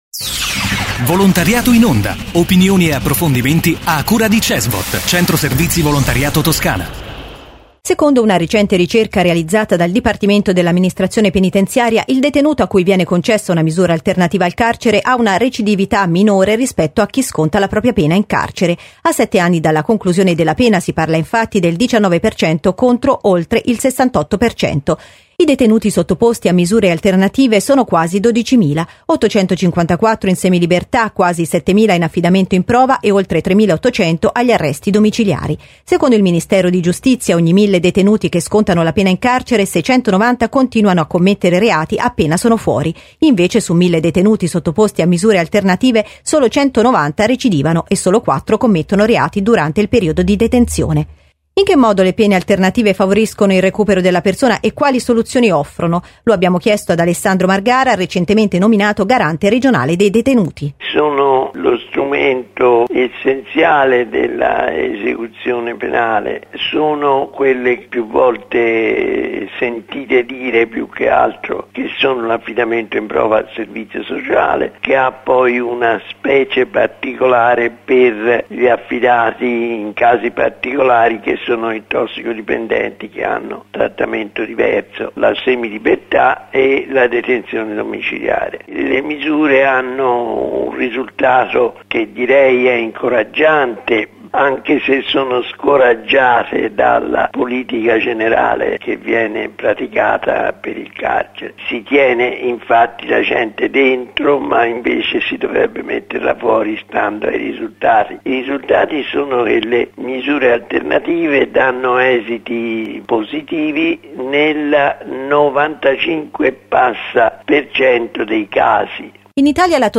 Intervista a Alessandro Margara, garante regionale dei detenuti.